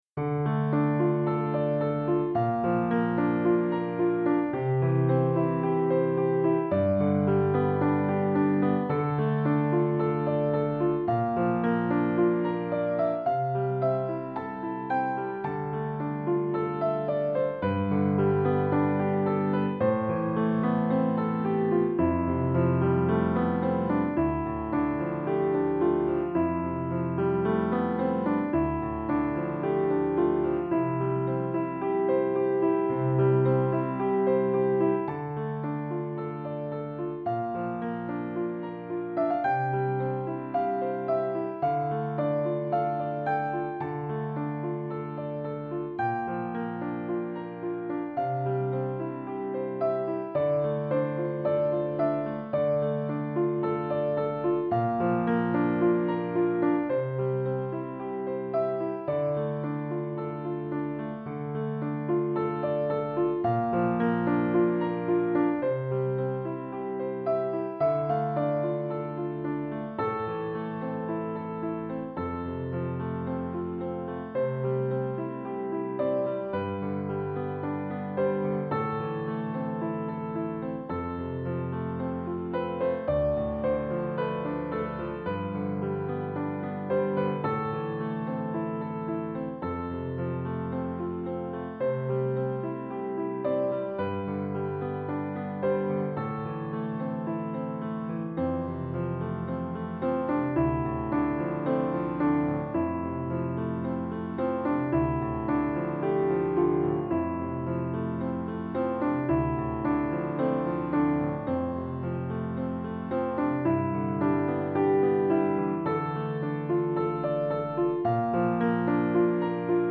Le but étant de faire une OST... d'un jeu qui n'existe pas !